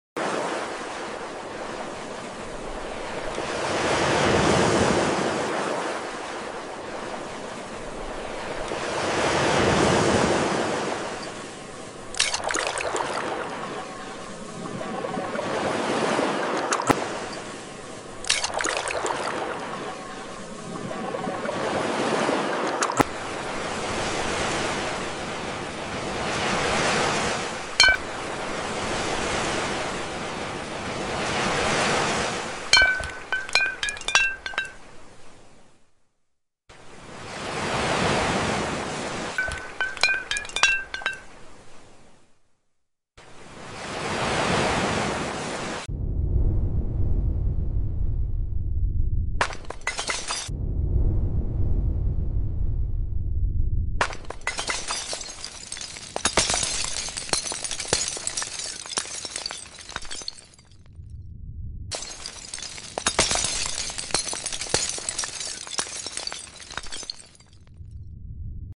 Experience ocean waves like never sound effects free download
Glass-textured shores, crystal sunsets, and surreal crashing waves. A soothing ASMR journey into calm relaxation.